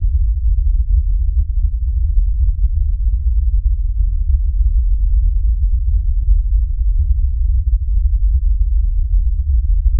deep-rumble-bass-distorte-geizq3ag.wav